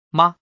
The neutral tone No mark like in ma Light (